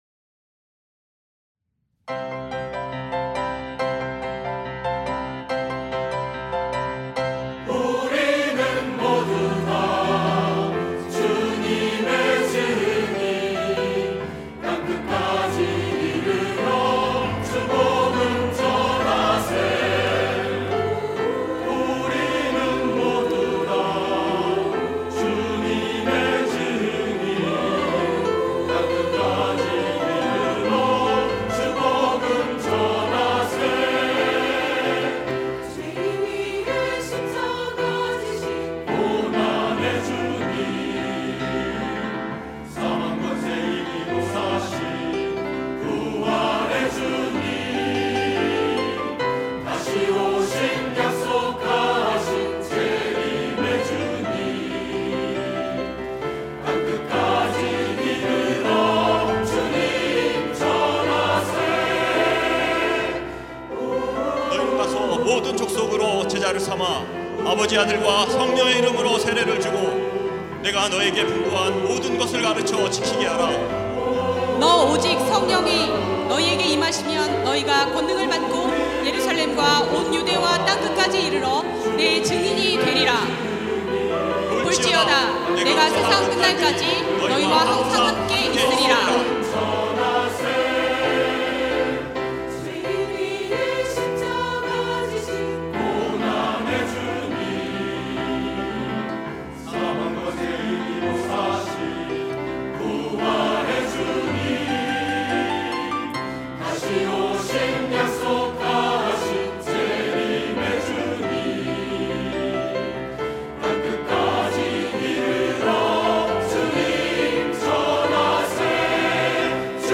할렐루야(주일2부) - 내 증인이 되리라
찬양대